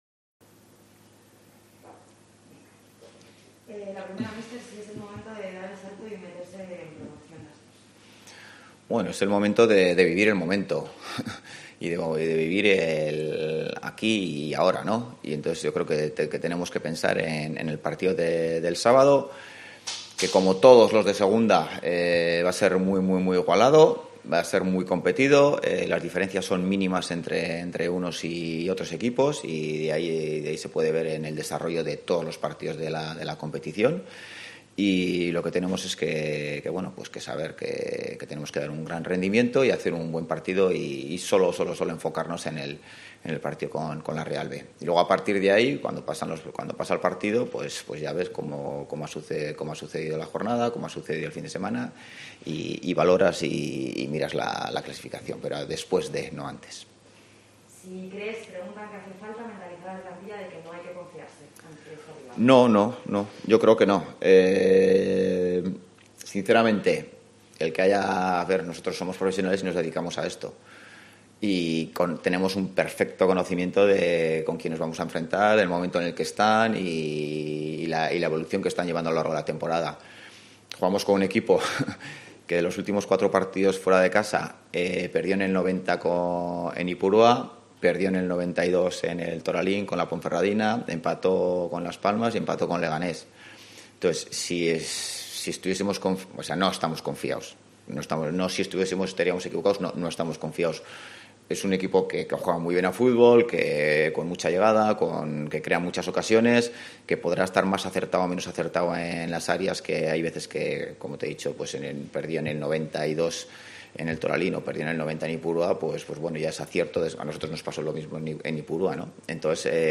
Rueda de prensa Ziganda (previa Real Sociedad B)